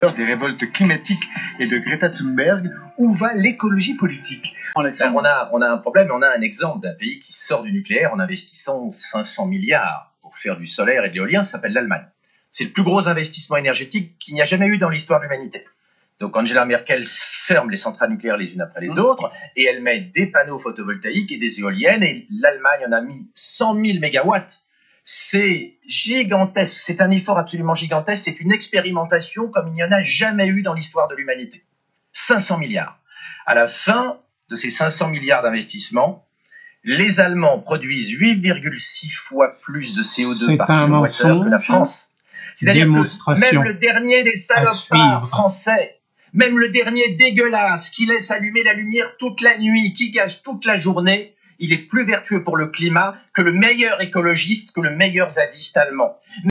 Phrase vociférée par Laurent ALEXANDRE (urologue, essayiste en cour au RN et fondateur de Doctissimo) sur France Inter le 17 février 2020.